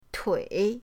tui3.mp3